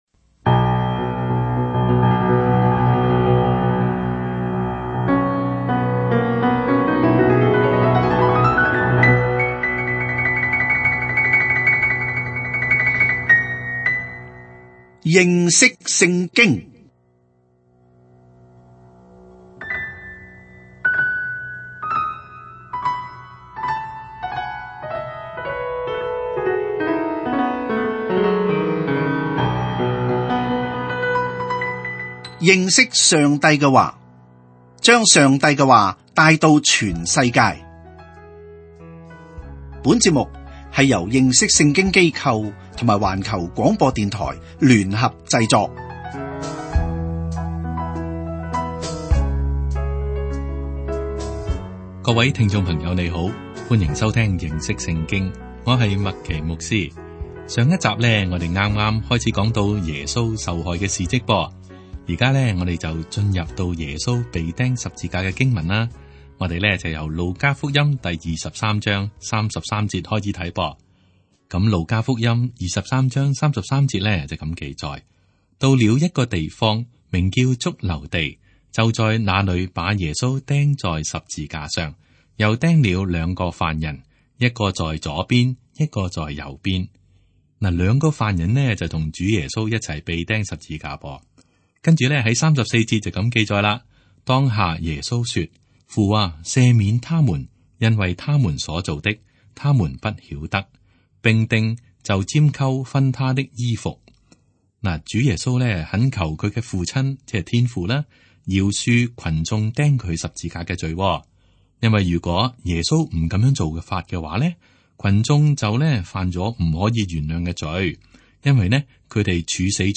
這是個每天30分鐘的廣播節目，旨在帶領聽眾有系統地查考整本聖經。